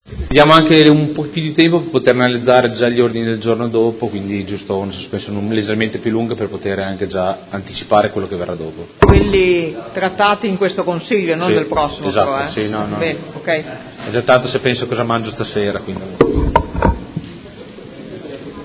Seduta del 15/11/2018 Ordine del giorno nr. 179789 - Massima divulgazione alla città dei contenuti della riforma deli istituti partecipativi dl comune.